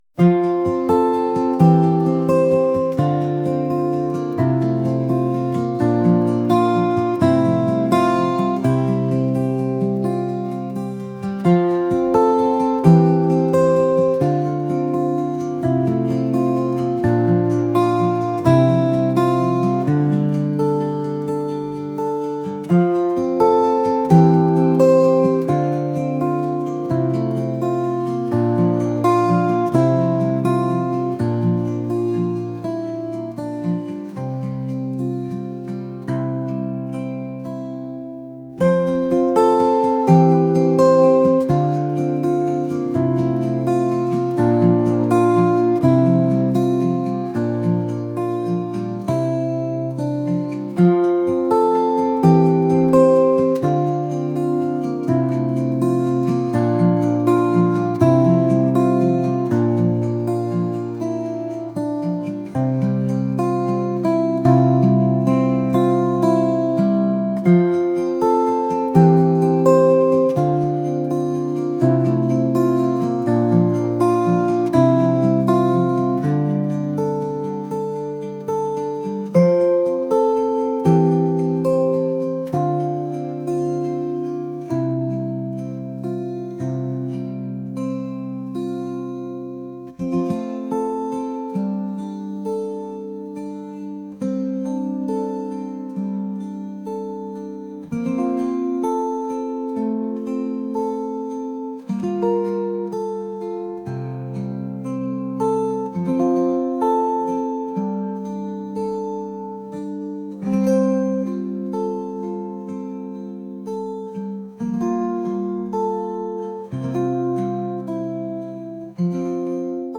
folk | acoustic | ambient